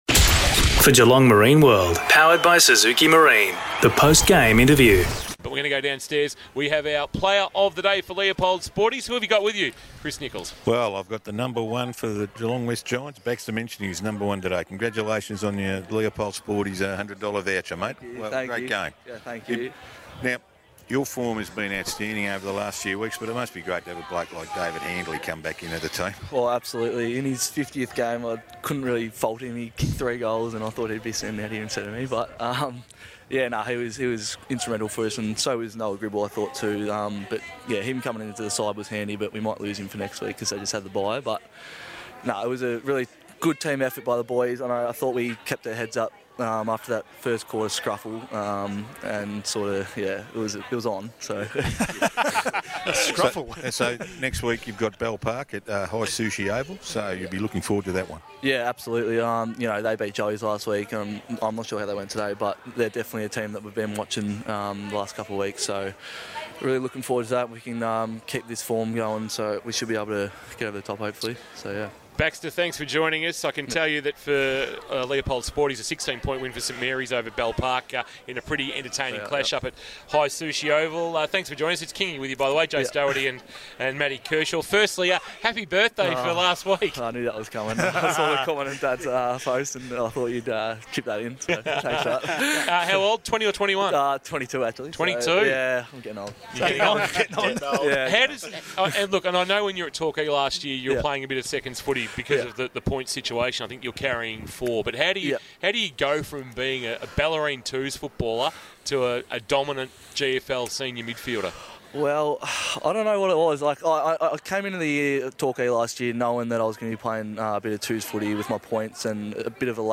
2022 – GFL ROUND 9 – GEELONG WEST vs. NORTH SHORE: Post-match Interview